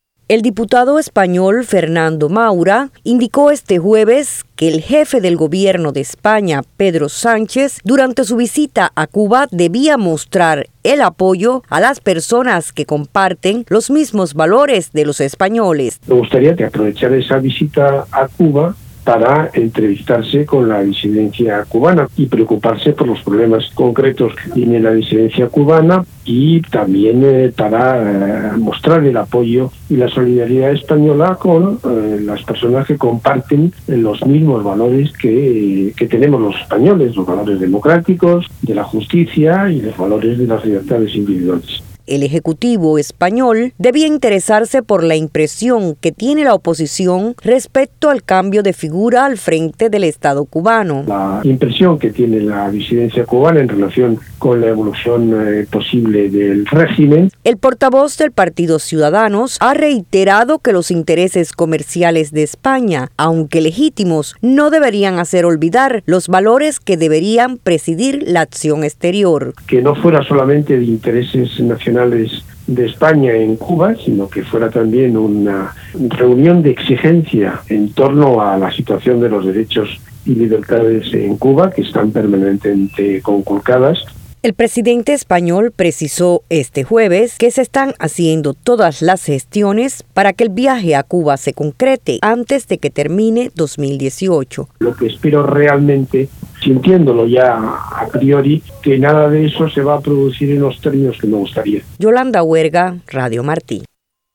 En declaraciones a Radio Martí sobre la anunciada visita el diputado del partido Ciudadanos por Madrid, Fernando Maura, dijo que Sánchez debería mostrar durante su visita apoyo a las personas que comparten los mismos valores que los españoles, tales como la democracia, la justicia y las libertades individuales.